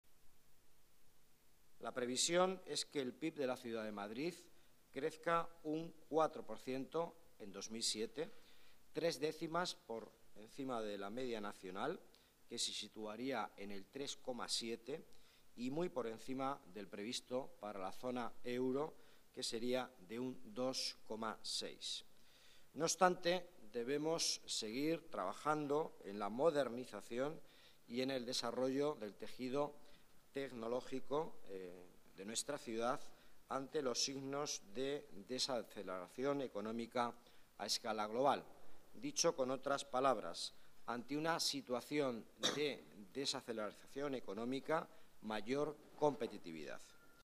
Nueva ventana:Declaraciones delegado Economía, Miguel Ángel Villanueva: datos optimistas